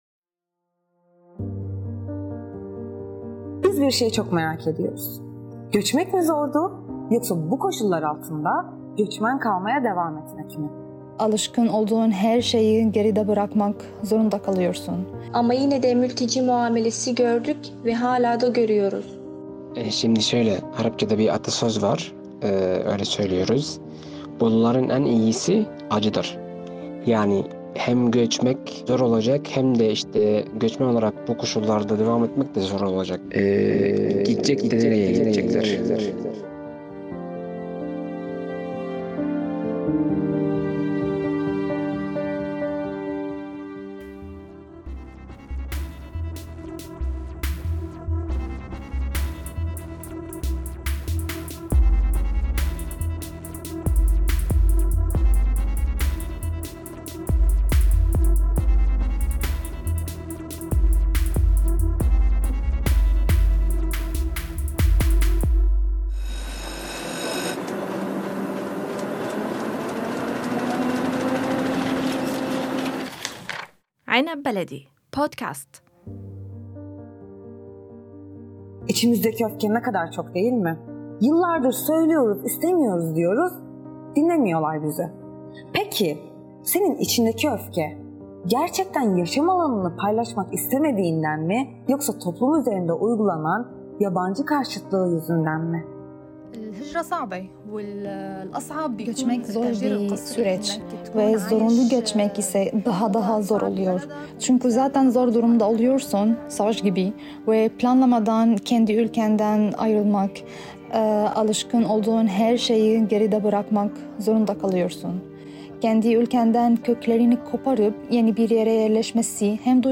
Gitmek mi zor, yoksa bu koşullarda göçmen kalmaya devam etmek mi? Birbirinden farklı ve renkli hayatlara sahip tek bir kavramda, ”göçmen” kavramında buluşan konuklarımızın cevaplarını aldık..